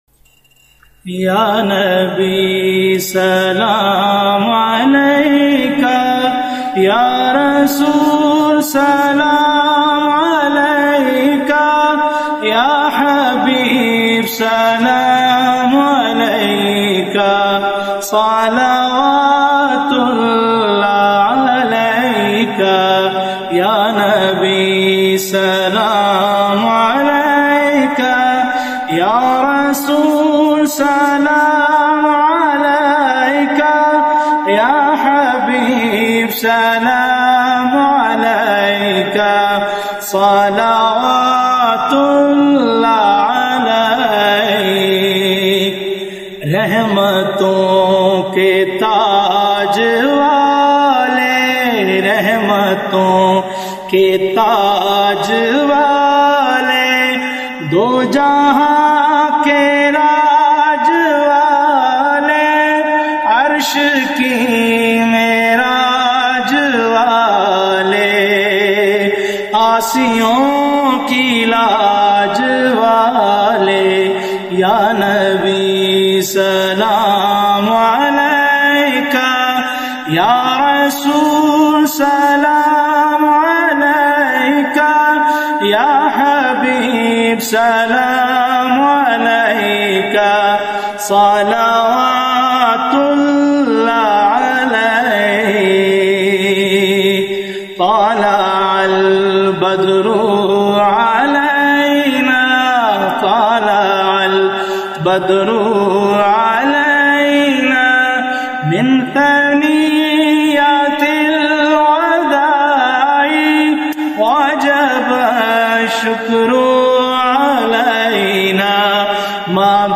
naat
God gifted sweet voice